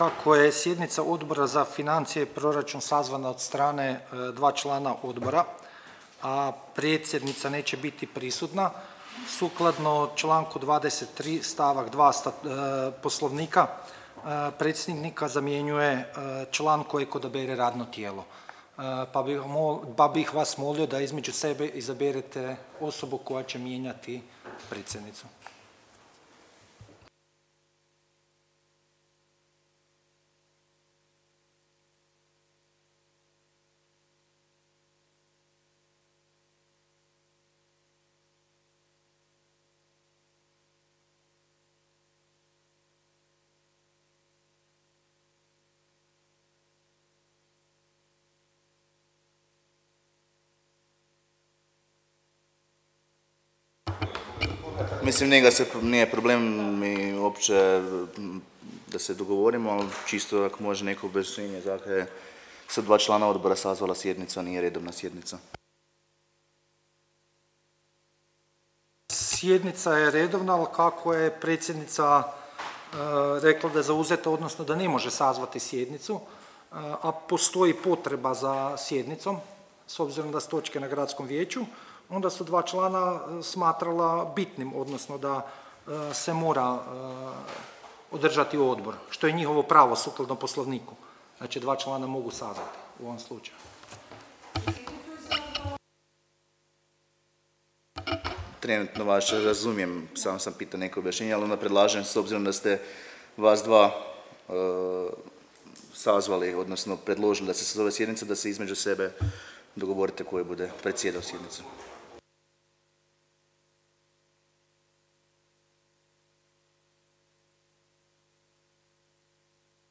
Obavještavam Vas da će se 16. sjednica Odbora za financije i proračun Gradskog vijeća Grada Čakovca održati dana 11. rujna 2023. (ponedjeljak), u 09.30 sati, u gradskoj vijećnici Grada Čakovca.